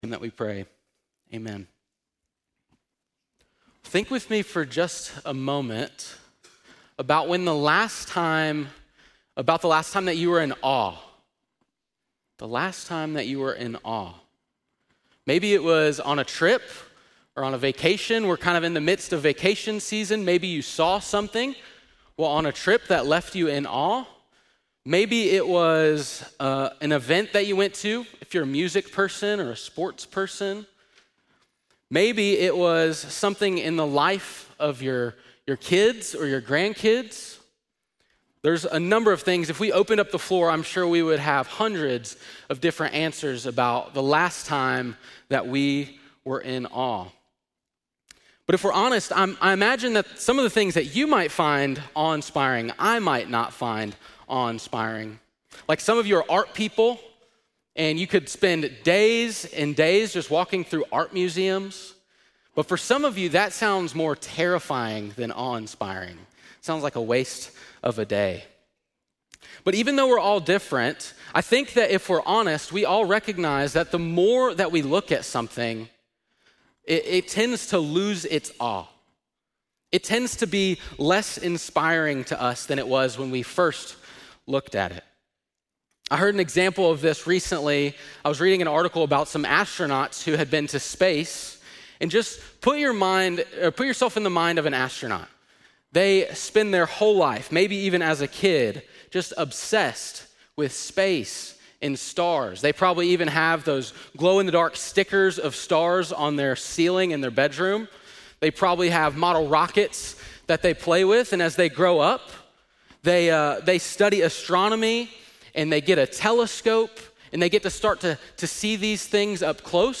7.13-sermon.mp3